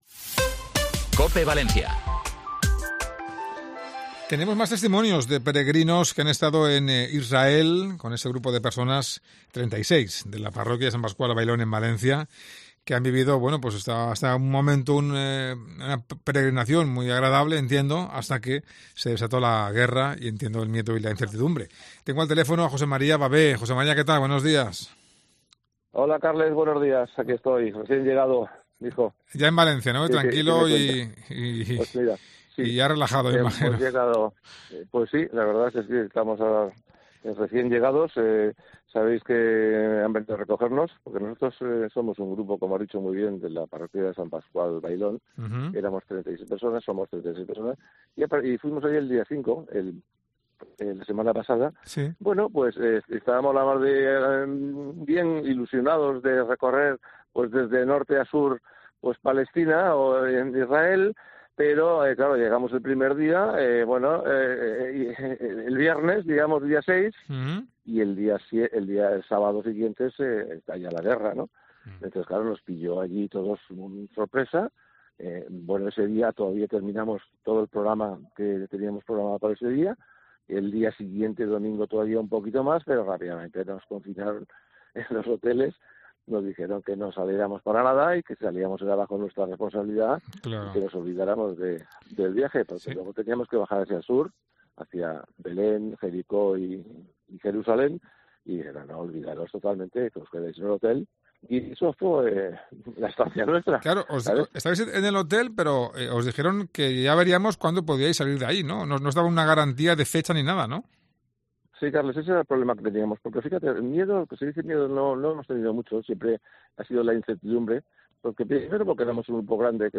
Un peregrino valenciano cuenta en COPE su vuelta de la guerra de Israel: "Dónde me he metido"